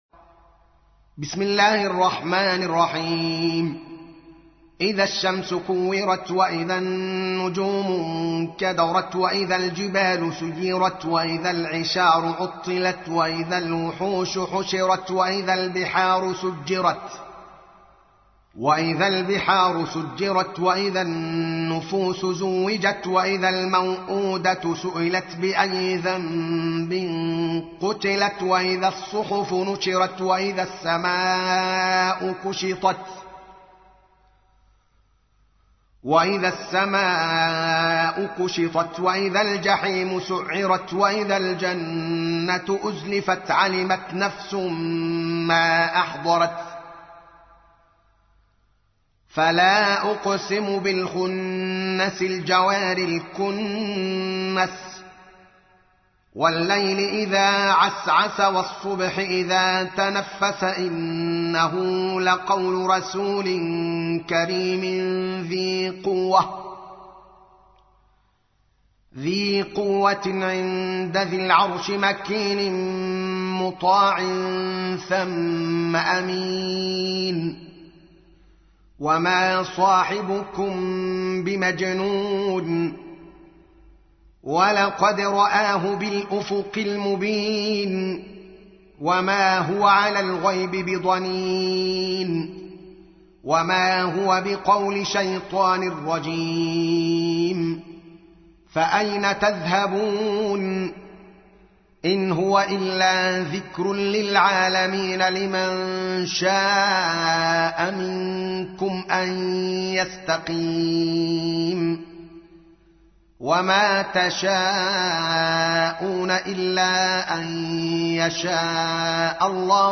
تحميل : 81. سورة التكوير / القارئ الدوكالي محمد العالم / القرآن الكريم / موقع يا حسين